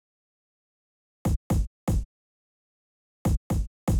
34 Counter Kick.wav